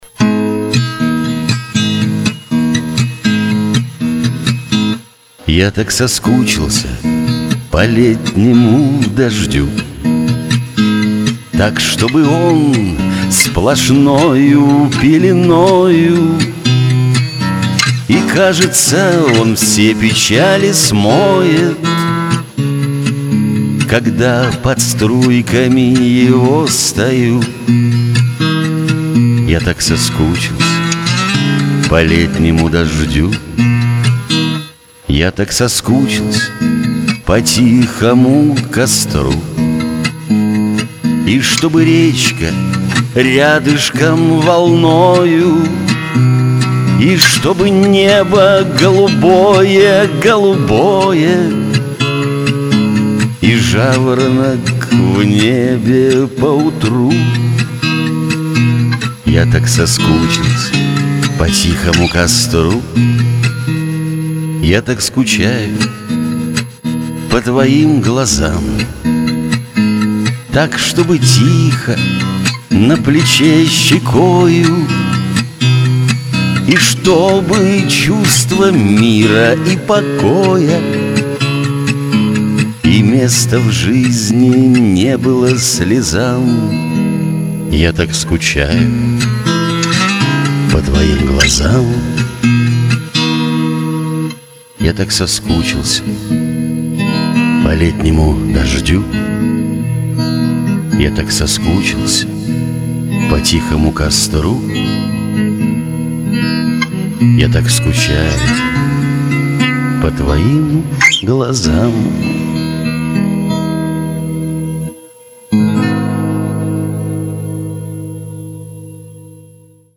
Правда, "на коленке" записано, делайте скидку))))
Одна - шуточная - "Про стихи_алчное" получилась, другая - лирическая "Я так скучаю".
Изумительный голос и блестящая игра на гитаре!!!